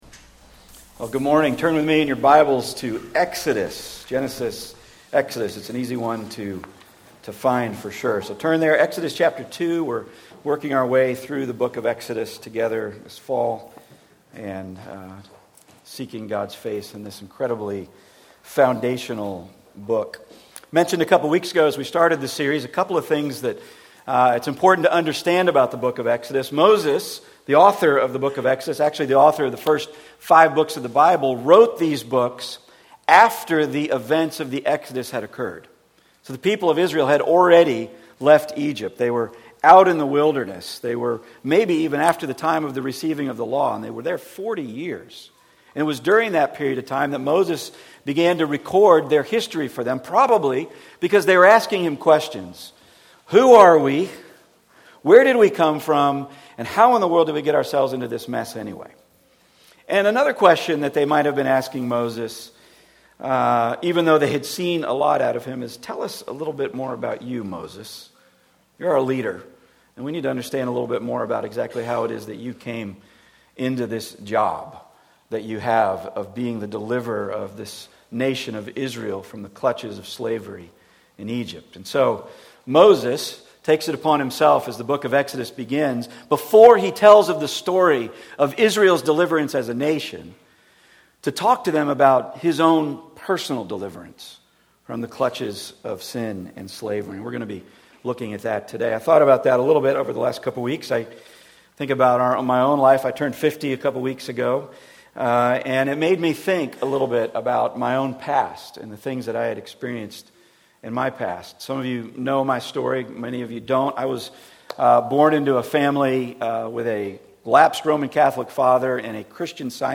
Passage: Exodus 2:11-25 Service Type: Weekly Sunday